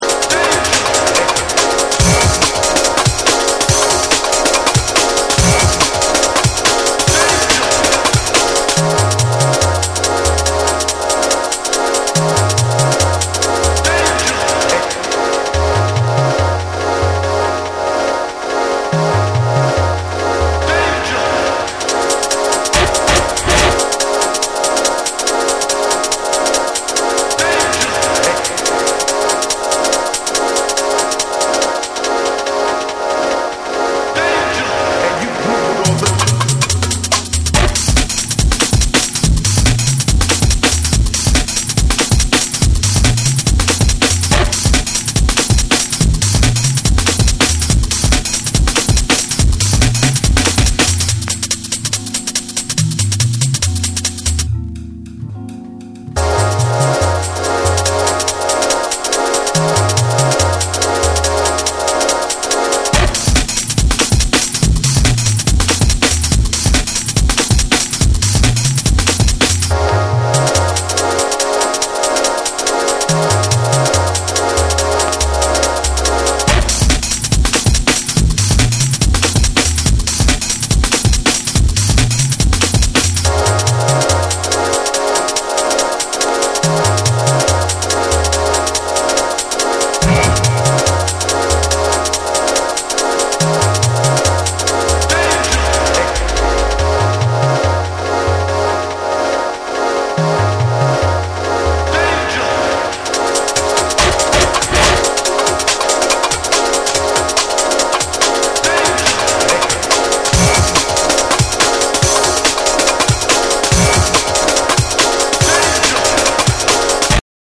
変則的に刻まれるファットでトライバルなビートにアトモスフェリックな上音が一体となる
BREAKBEATS